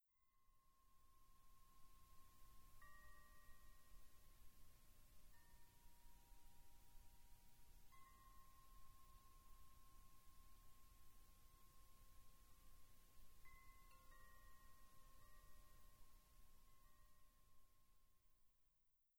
ModChimes produce beautiful, distinct, bell-like tones
A3 D4 G4 C5 F4